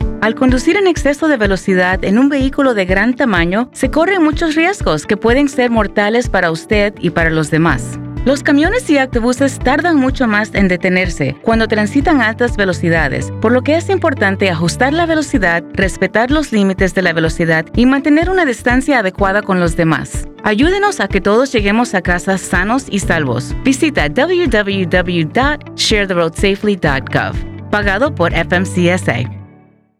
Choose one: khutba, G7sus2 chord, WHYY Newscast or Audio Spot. Audio Spot